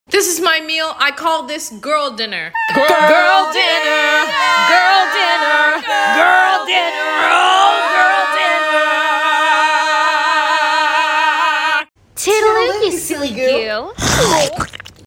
TikTok Audios